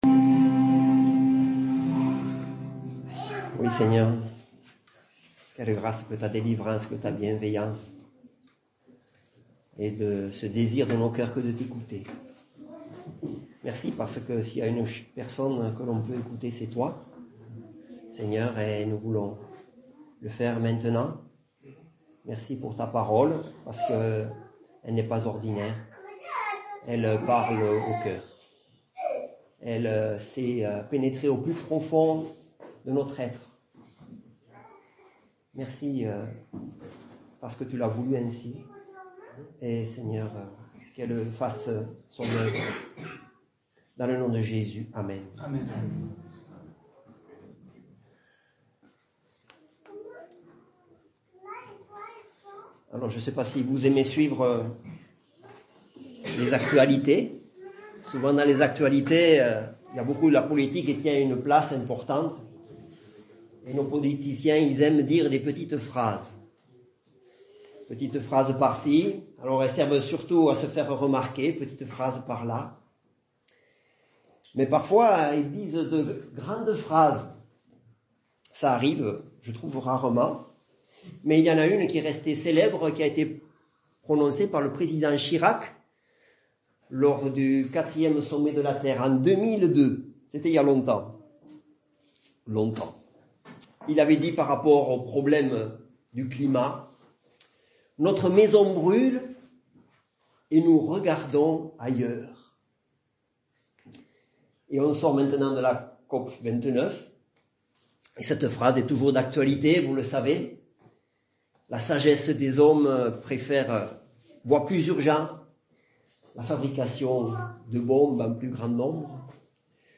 Baptême du dimanche 1 décembre 2024 - EPEF
Baptême